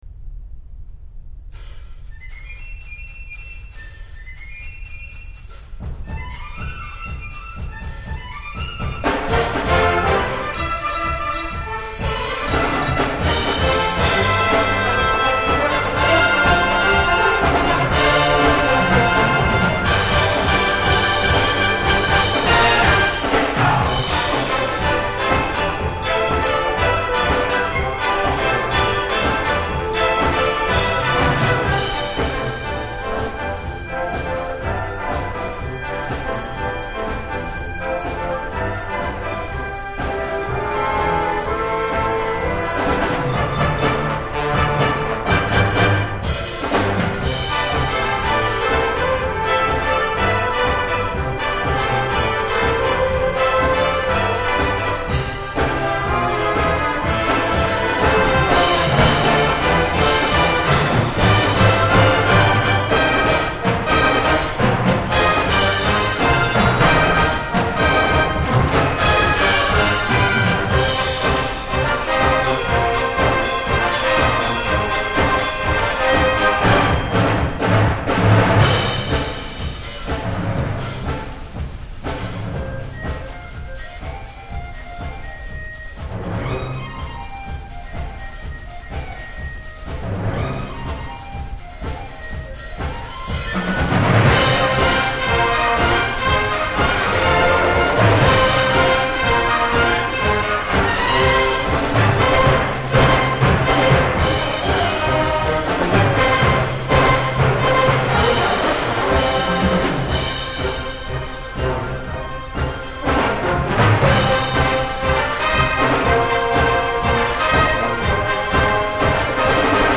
2006.2.5 サンピアン川崎